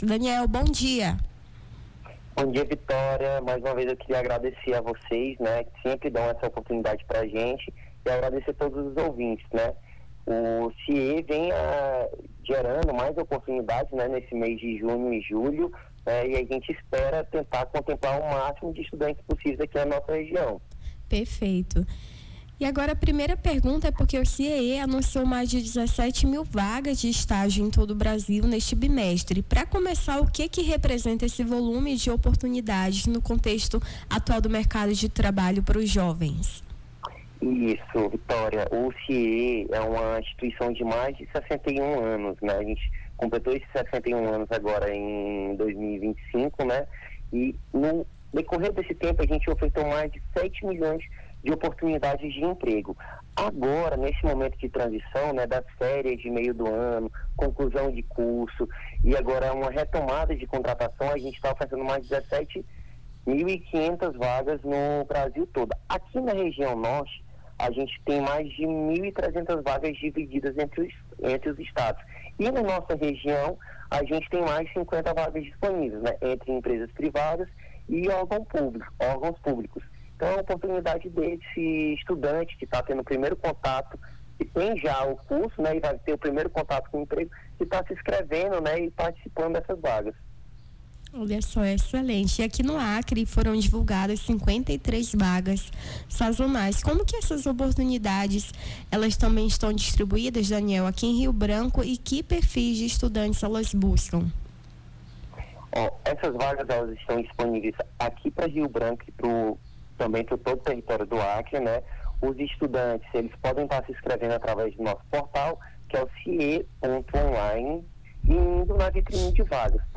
Nome do Artista - CENSURA - ENTREVISTA (VAGAS CIEE) 11-07-25.mp3